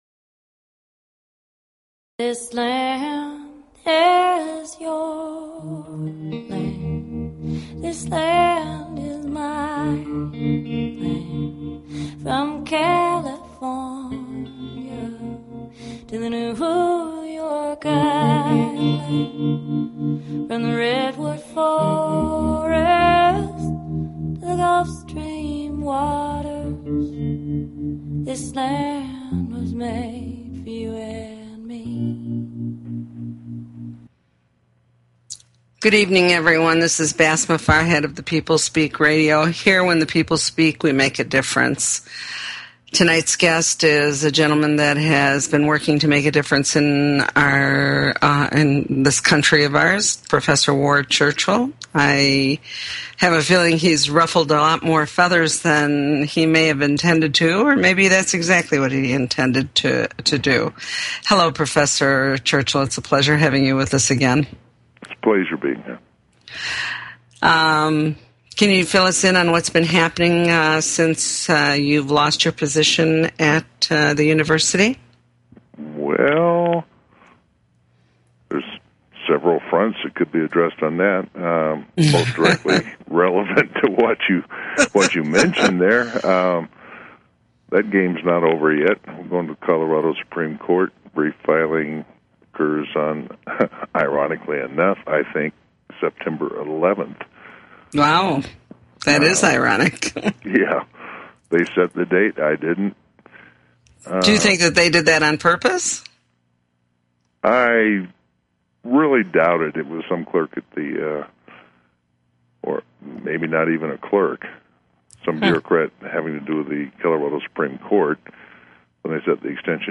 Talk Show Episode, Audio Podcast, The_People_Speak and Ward Churchill on , show guests , about , categorized as Education,Politics & Government,Society and Culture
Guest, Ward Churchill